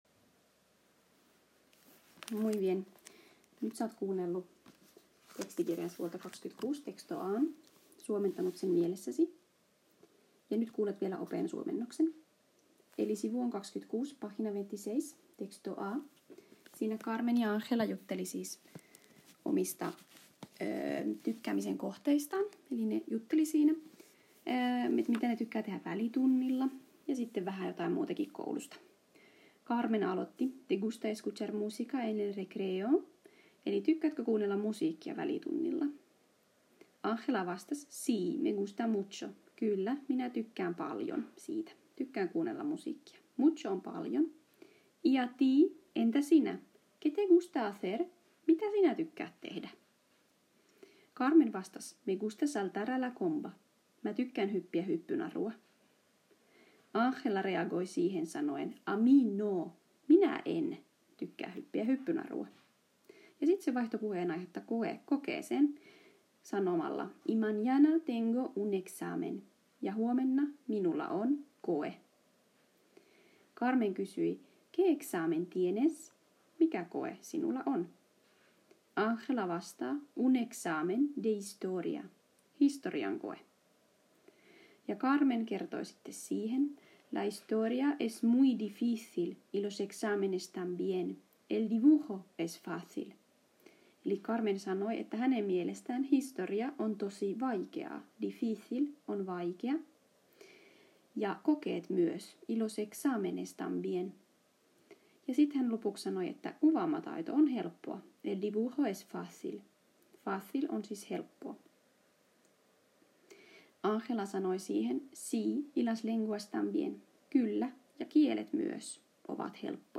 Kun olet valmis, kuuntele alta vielä open esimerkkisuomennos painamalla play.